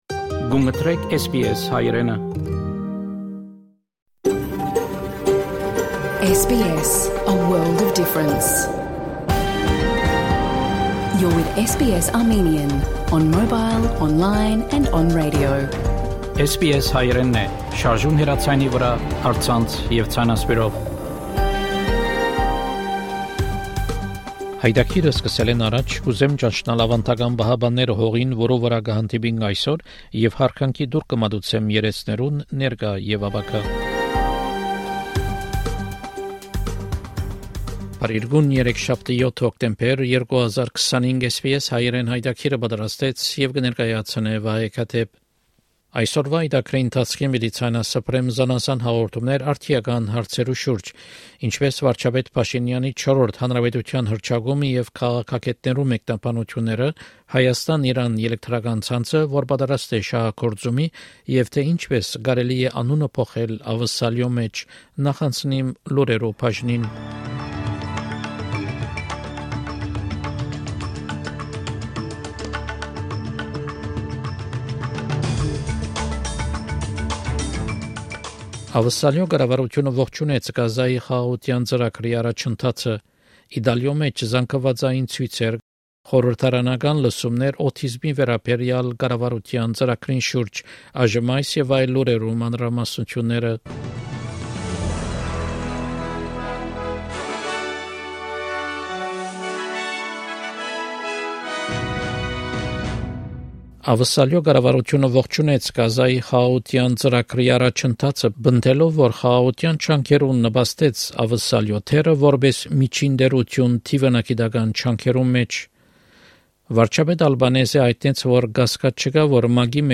SBS Armenian news bulletin from 7 October 2025 program.